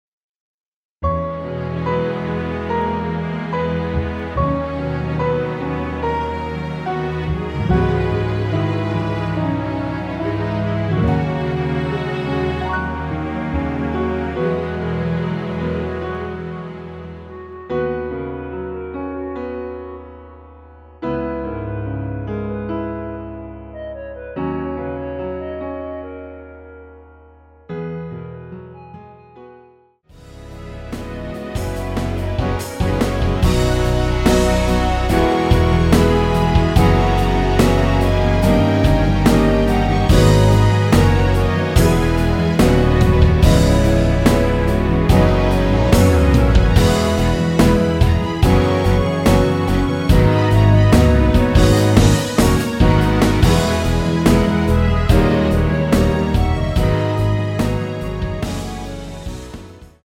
원키에서 (-3)내린 멜로디 포함된 MR 입니다.(미리듣기 참조)
앞부분30초, 뒷부분30초씩 편집해서 올려 드리고 있습니다.
중간에 음이 끈어지고 다시 나오는 이유는